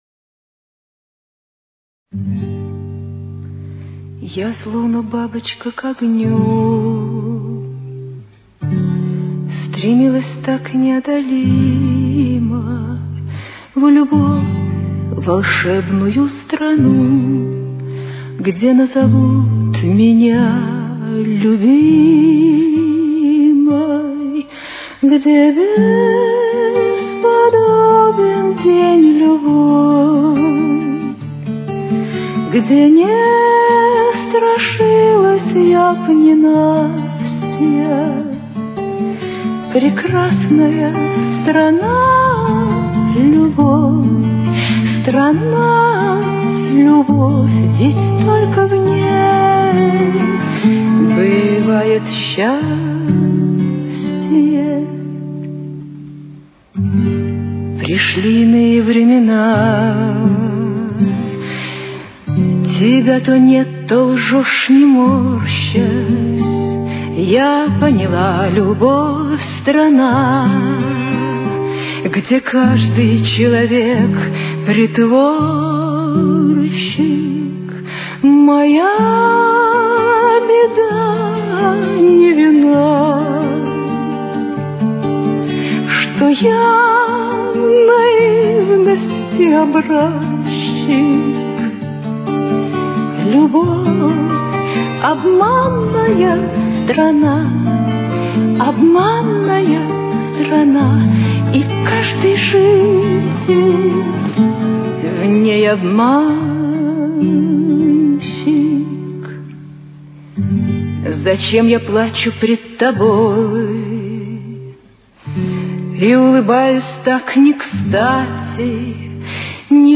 Соль минор.